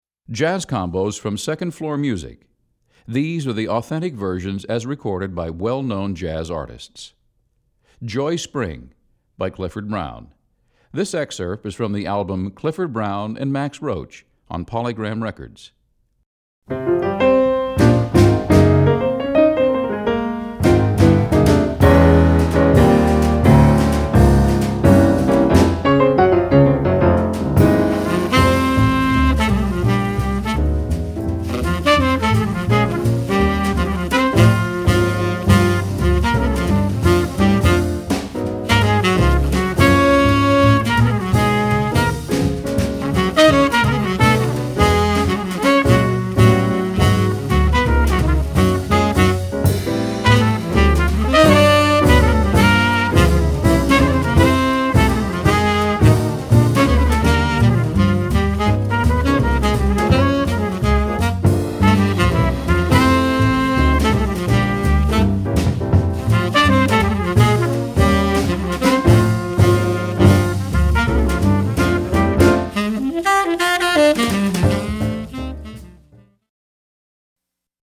Voicing: Combo Quintet